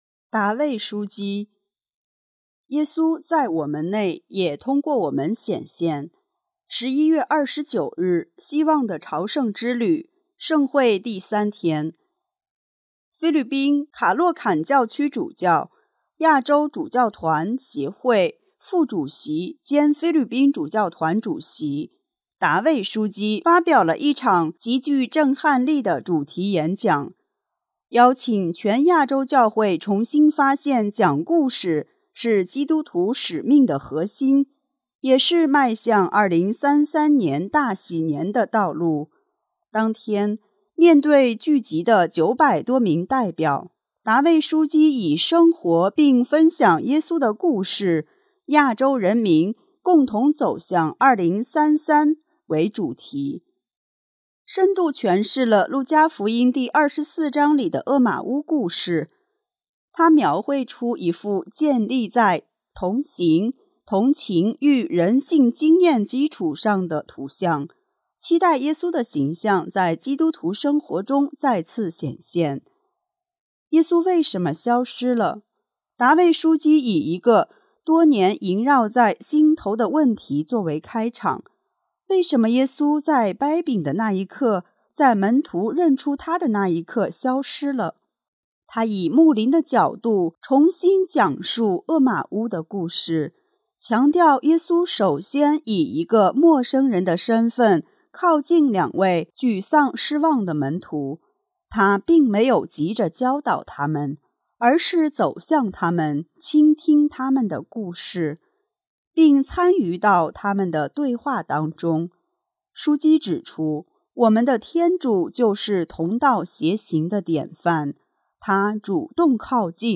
11月29日，“希望的朝圣之旅”盛会第三天。